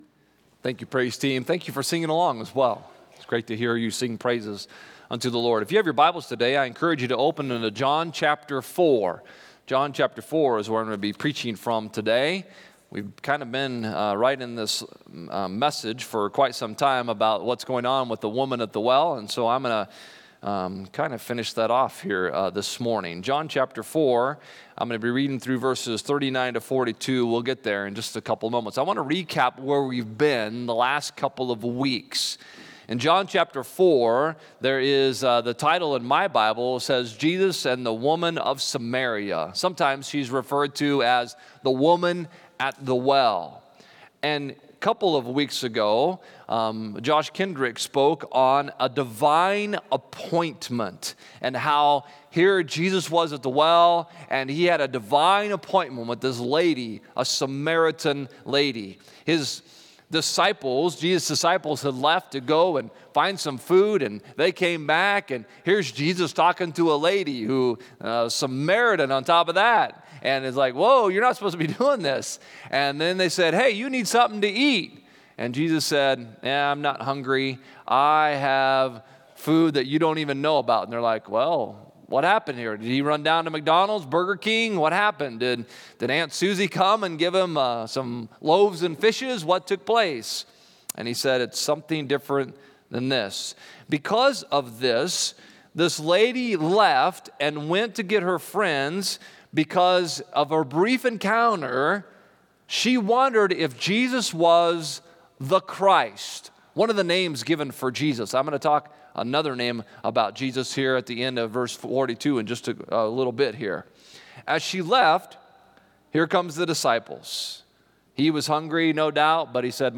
Click Here to Follow Along with the Sermon on the YouVersion Bible App Romans 1:26-27 English Standard Version 26 For this reason God gave them up to dishonorable passions.